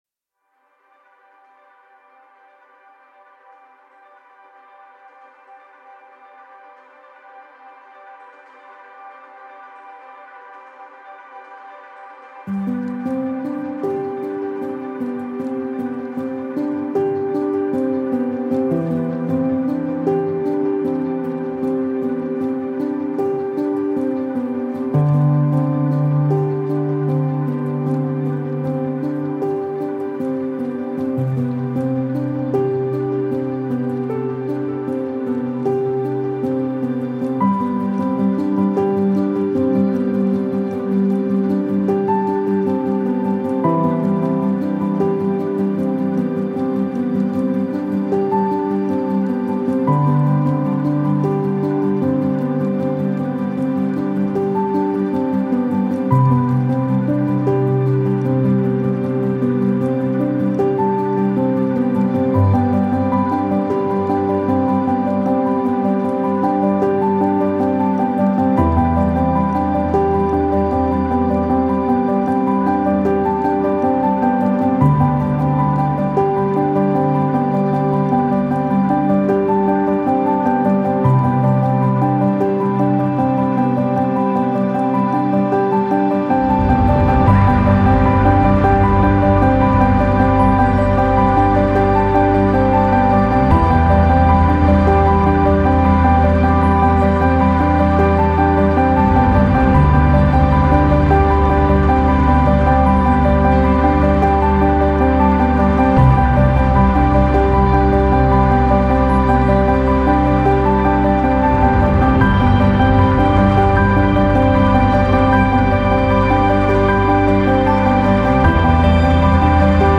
موسیقی امبینت